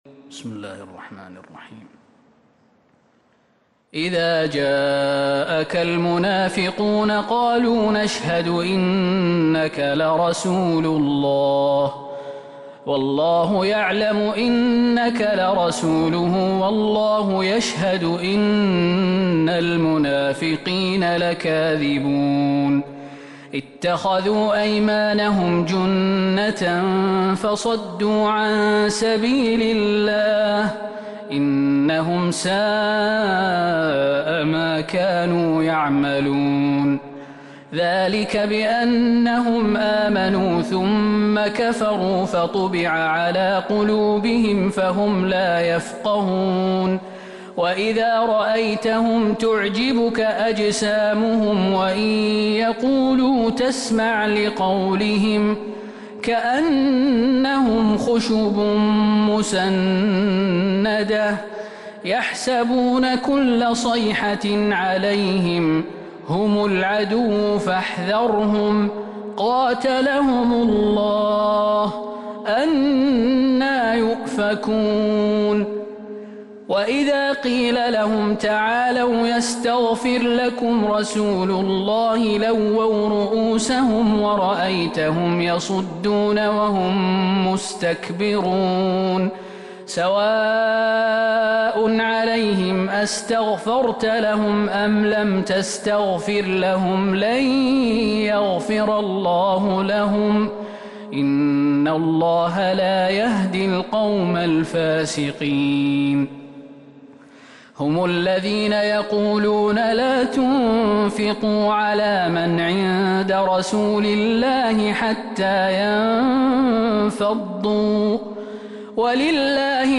سورة المنافقون Surat Al-Munafiqoon من تراويح المسجد النبوي 1442هـ > مصحف تراويح الحرم النبوي عام 1442هـ > المصحف - تلاوات الحرمين